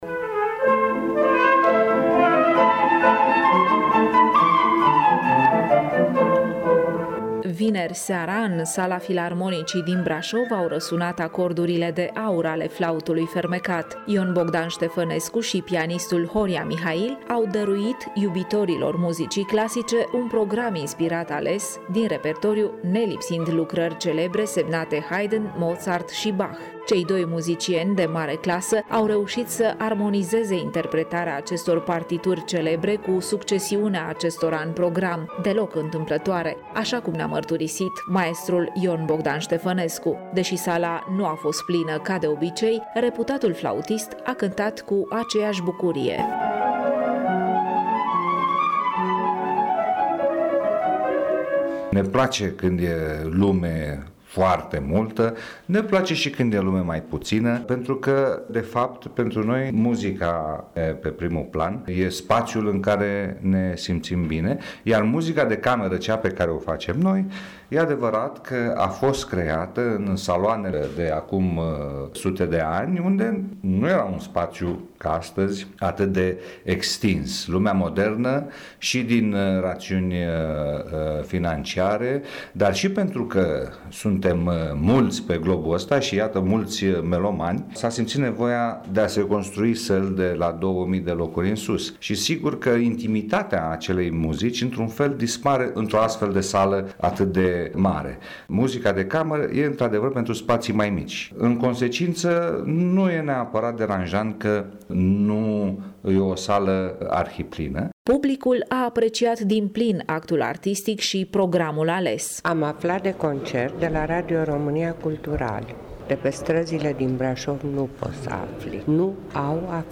Vineri seara, în sala Filarmonicii din Brașov au răsunat acordurile de aur ale Flautului fermecat. Ion Bogdan Ștefănescu și pianistul Horia Mihail au dăruit iubitorilor muzicii clasice un program inspirat ales.